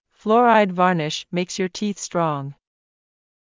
ﾌﾛｰﾗｲﾄﾞ ｳﾞｧｰﾆｯｼｭ ﾒｲｸｽ ﾕｱ ﾃｨｰｽ ｽﾄﾛﾝｸﾞ